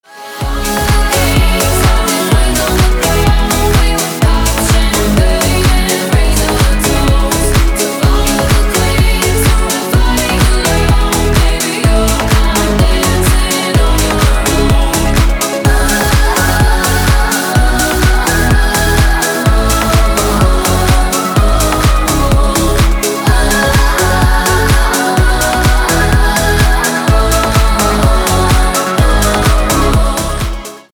• Качество: 320, Stereo
громкие
женский вокал
EDM
электронная музыка
house